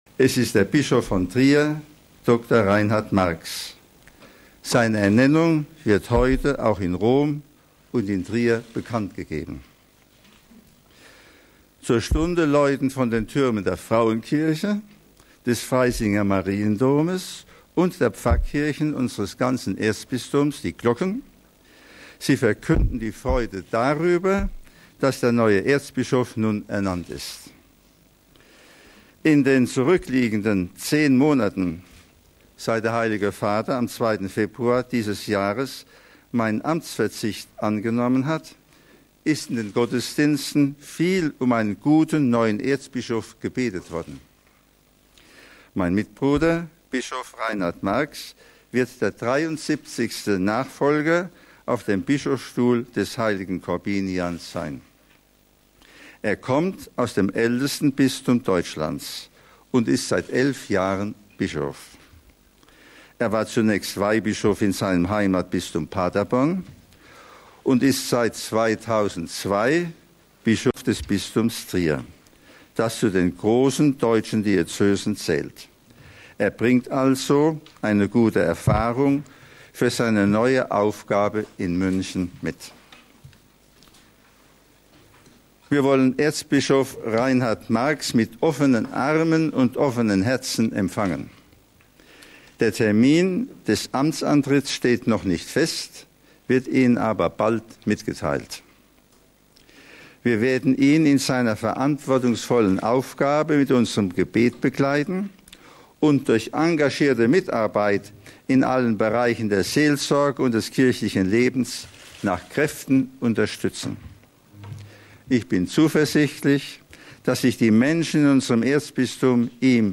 Er sei bereit, die Herausforderung anzunehmen, so Marx bei der ersten Pressekonferenz:
MP3 bei der Pressekonferenz am Freitag zur Ernennung zum Erzbischof von München-Freising.